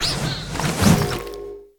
Cri de Forgella dans Pokémon Écarlate et Violet.